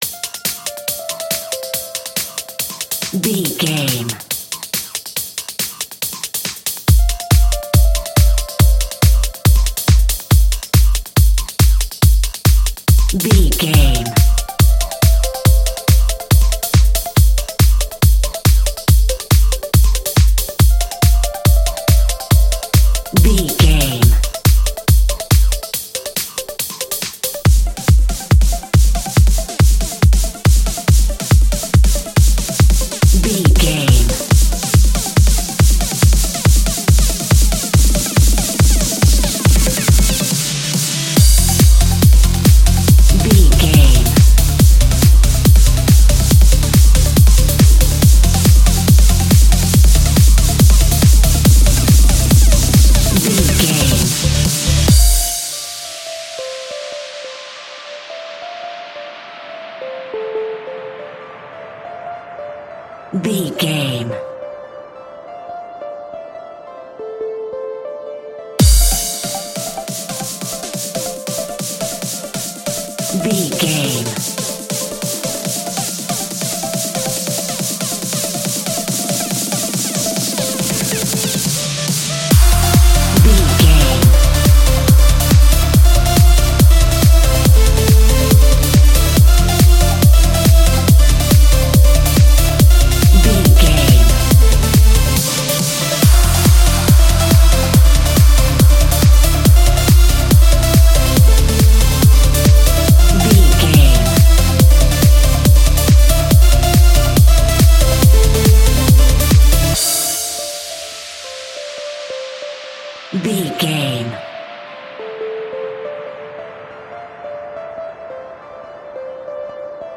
Epic / Action
Fast paced
In-crescendo
Aeolian/Minor
Fast
aggressive
powerful
dark
uplifting
driving
energetic
drums
synthesiser
drum machine
uptempo
synth leads
synth bass